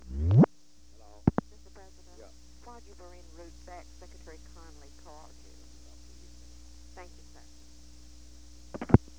Location: White House Telephone
The White House operator called the President.